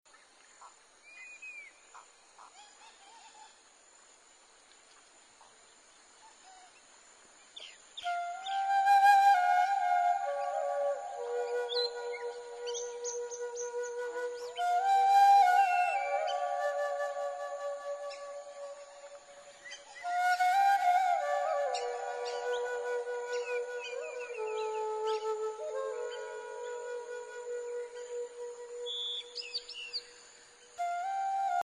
Ideal for relaxation and/or meditation